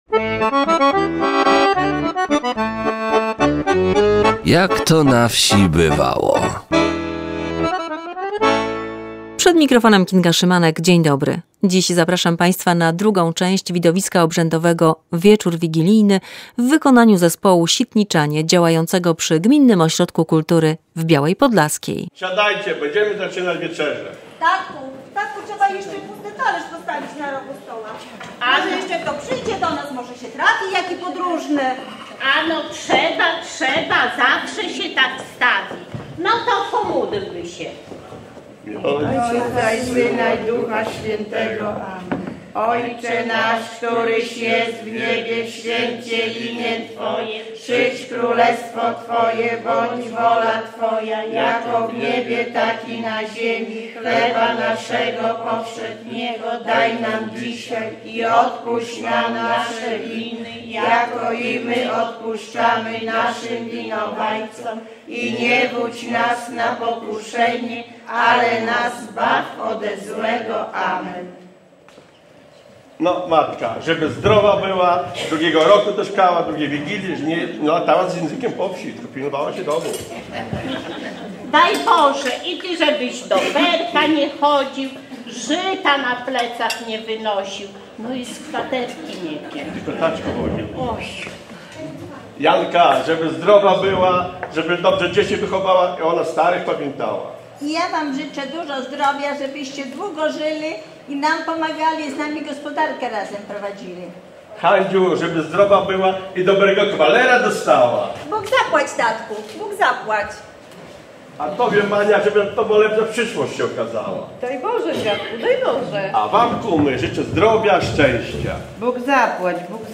Widowisko obrzędowe "Wieczór wigilijny" w wykonaniu zespołu Sitniczanie cz.2.